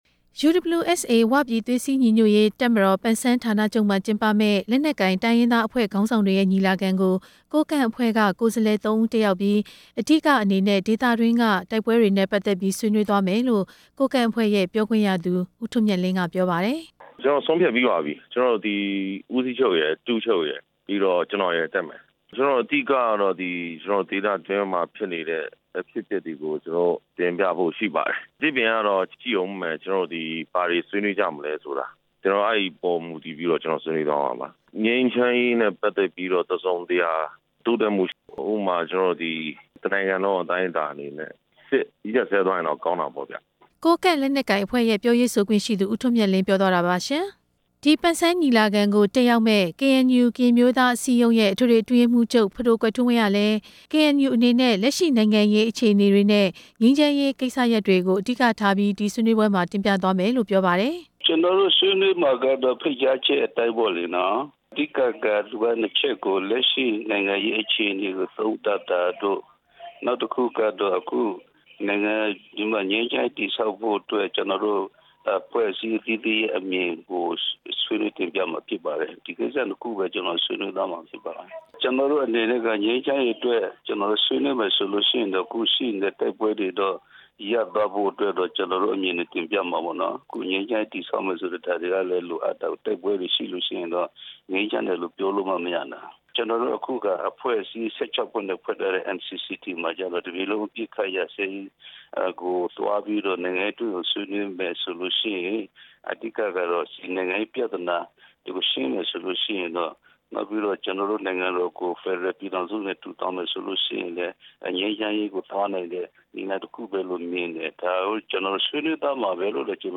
တင်ပြချက်